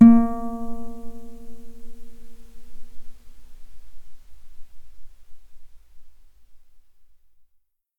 A#3_ff.mp3